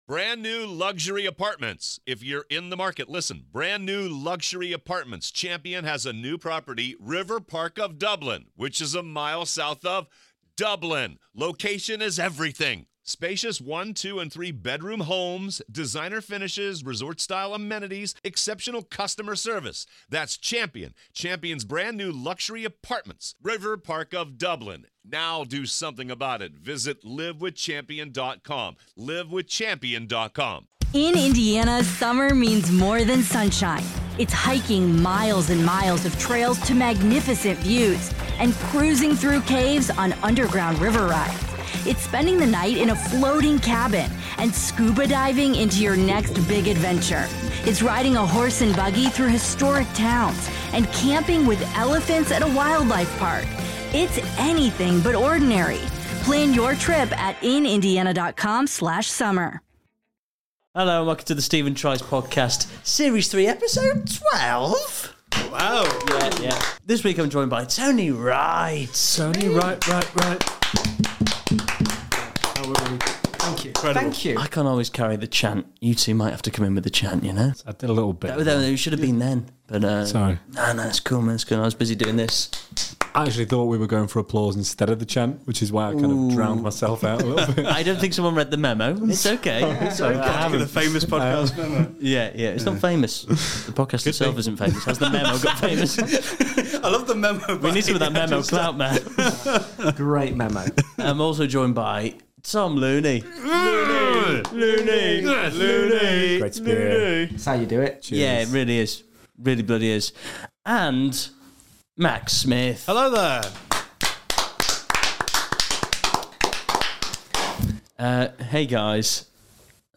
Comedy Interviews, Comedy